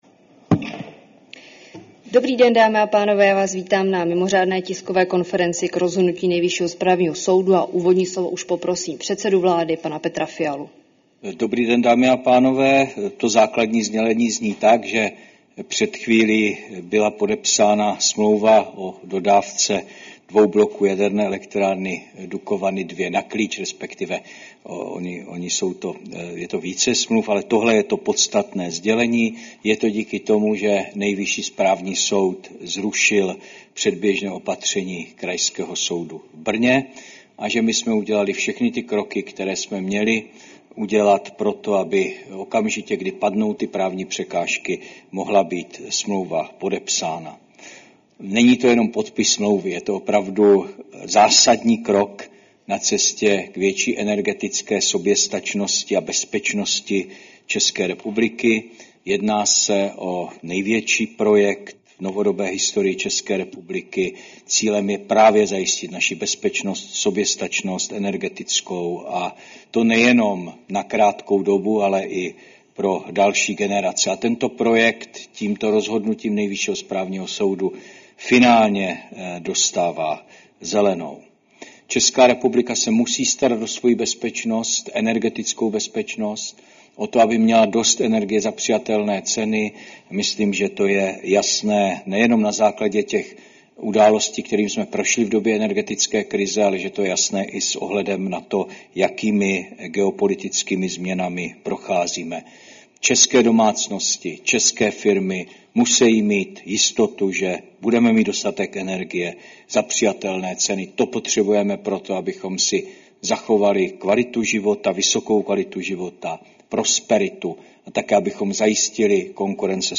Mimořádná tisková konference k rozhodnutí Nejvyššího správního soudu, 4. června 2025.